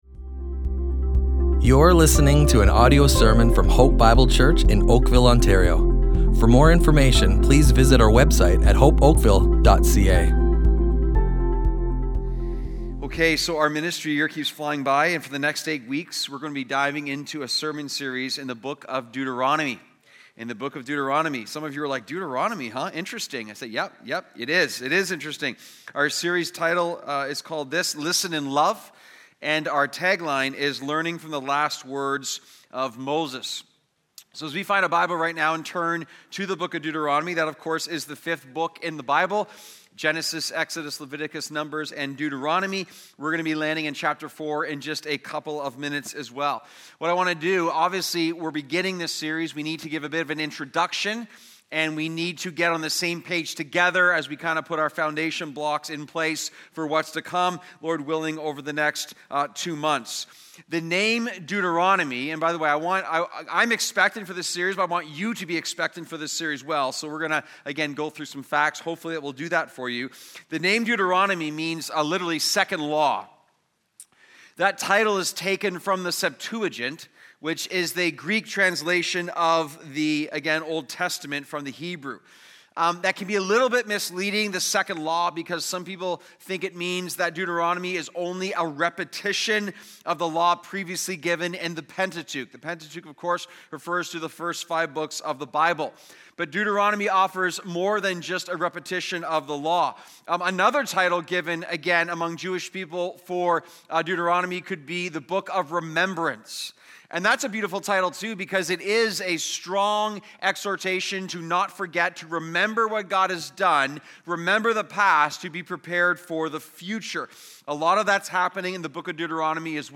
Hope Bible Church Oakville Audio Sermons Listen and Love // Watch Yourselves!